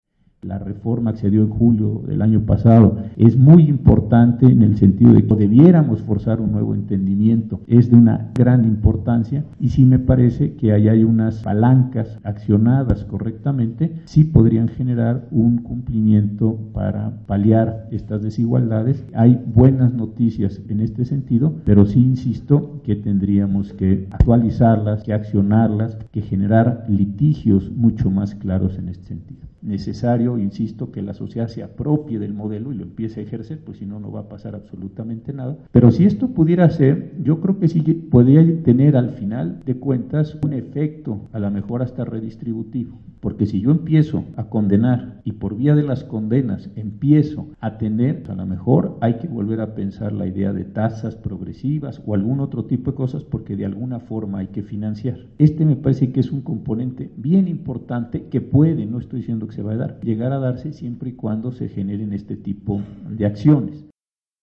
En el auditorio Narciso Bassols, José Ramón Cossío Díaz, ministro de la Suprema Corte de Justicia de la Nación (SCJN), sostuvo que la reforma constitucional que garantiza a los mexicanos el goce de los derechos humanos establecidos en la Carta Magna, y aquellos contenidos en cualquier tratado internacional celebrado por el Estado mexicano, es una modificación de gran relevancia para la vida nacional.
En conferencia magistral, recordó que todas las autoridades del país están obligadas a proteger, garantizar, respetar y salvaguardarlos.